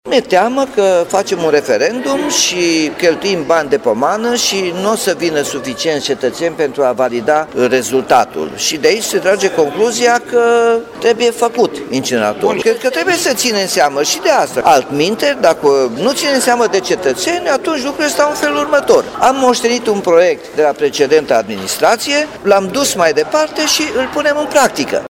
Primarul Nicolae Robu a declarat că apelează la consultarea publică pentru a-și feri numele de o investiție care ar putea polua.
nicolae-robu-incinerator.mp3